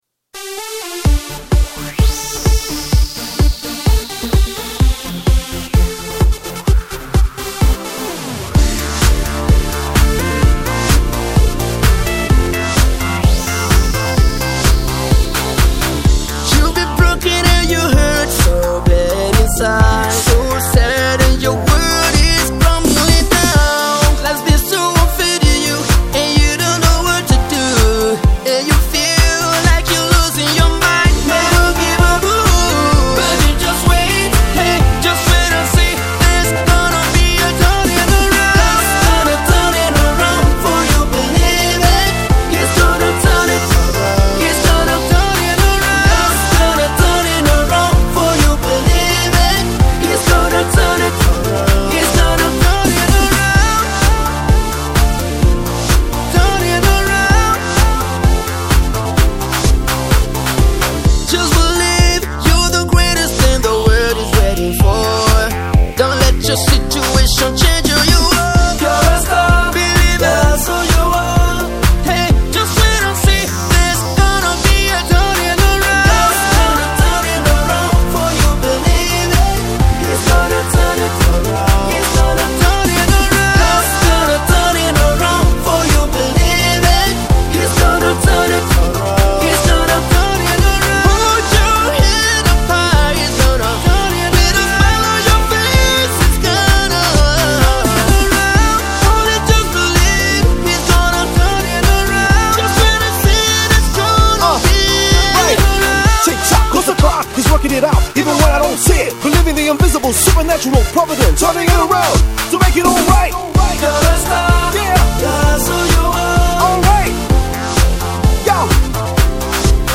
Gospel Singer by the name